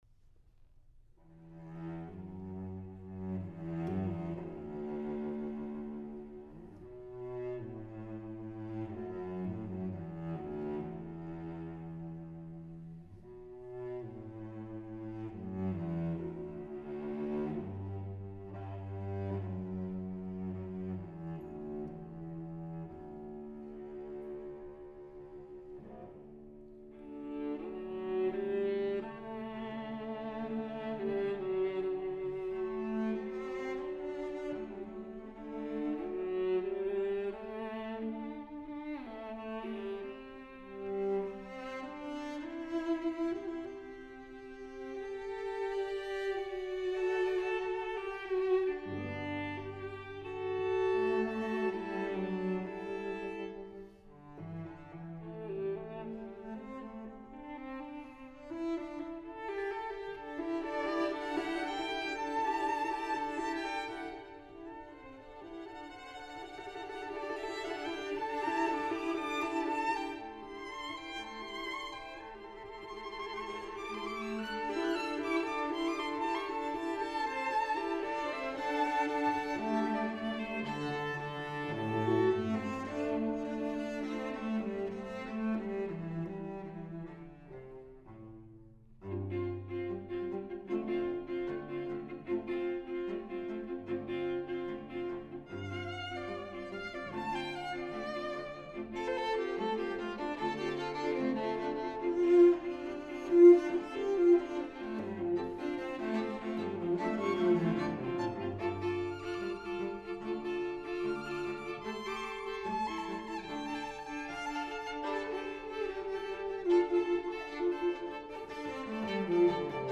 for String Trio (2011)